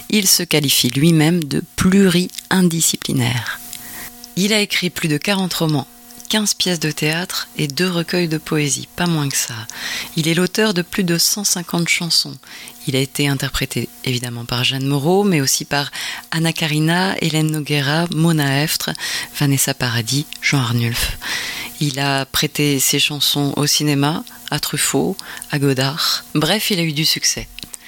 Extrait Voix émission REZVANI